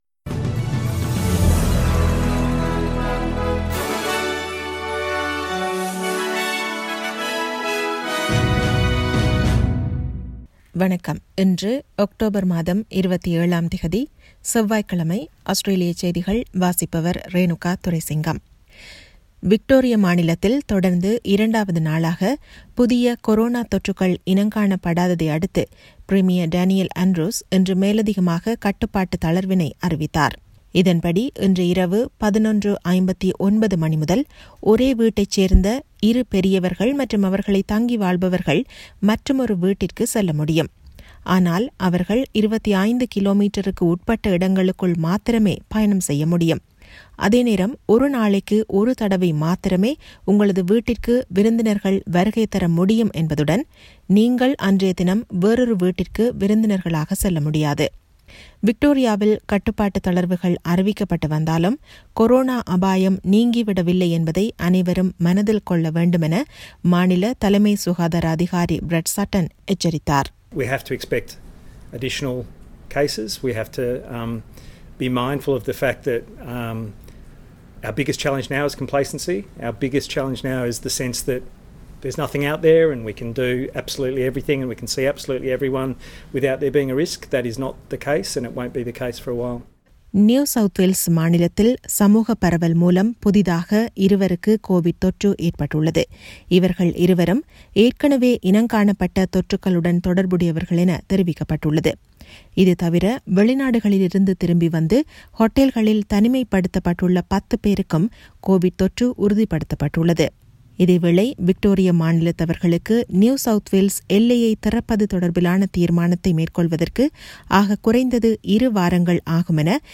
Australian news bulletin for Tuesday 27 October 2020.